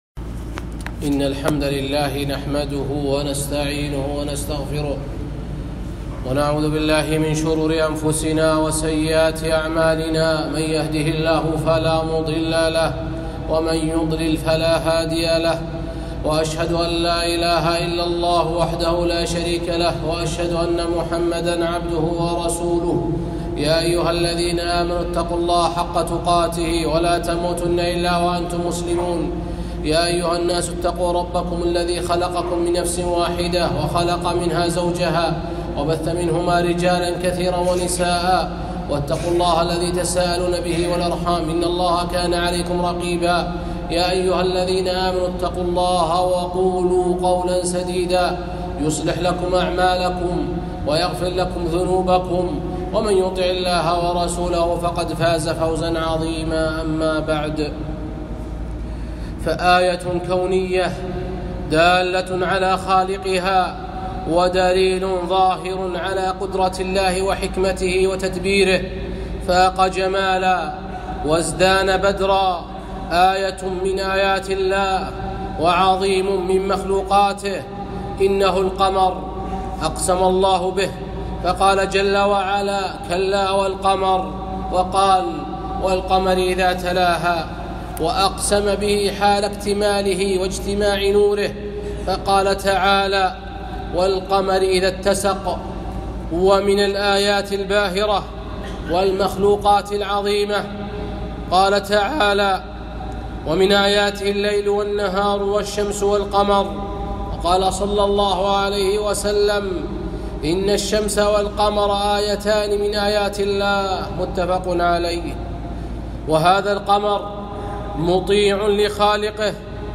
خطبة - الـقـمـر